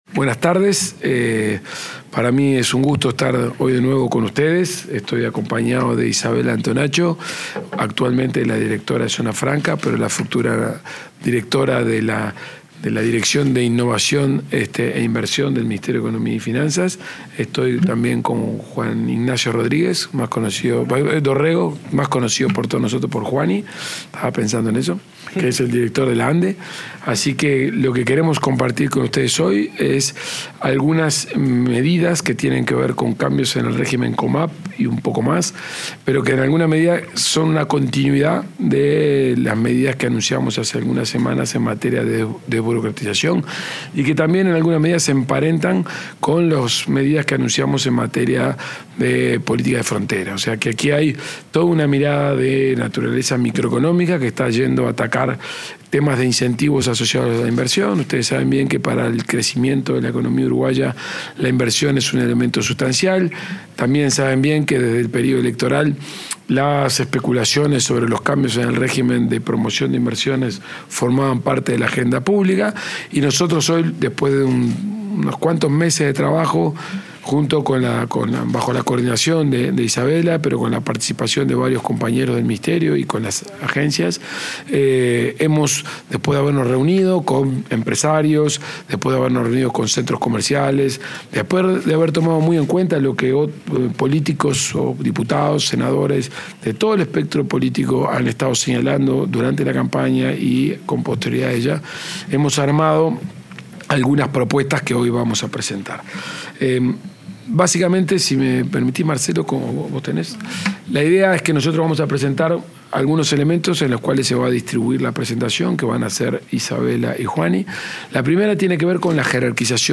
Palabras de autoridades del Ministerio de Economía y Finanzas
Palabras de autoridades del Ministerio de Economía y Finanzas 19/08/2025 Compartir Facebook X Copiar enlace WhatsApp LinkedIn El ministro de Economía, Gabriel Oddone; la directora nacional de Zonas Francas, Isabella Antonaccio, y el presidente de la Agencia Nacional de Desarrollo, Juan Ignacio Dorrego, anunciaron, en una conferencia de prensa, incentivos para las inversiones.